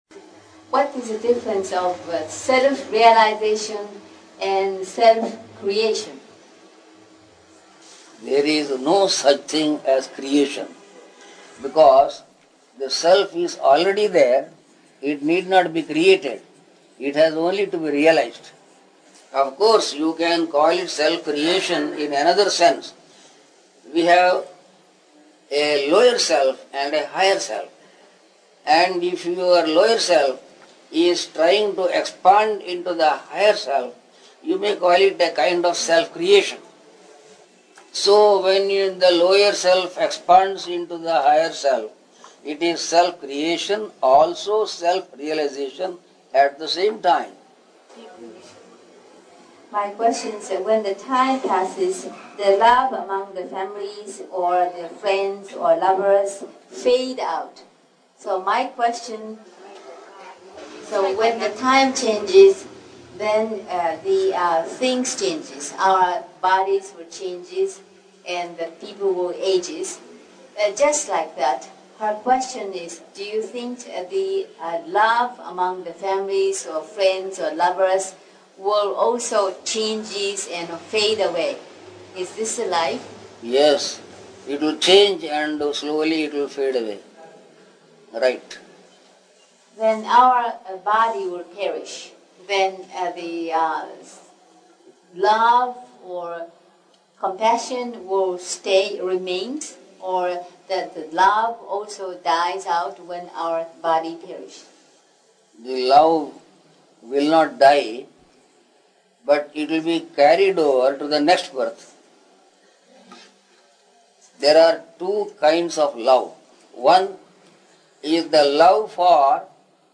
[Questions and answers from a group of Japanese visitors are being translated by a translator.]